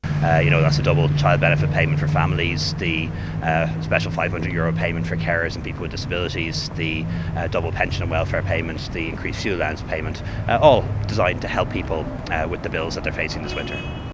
Tánaiste Leo Varadkar says people will be able to plan for the money with certainty from today: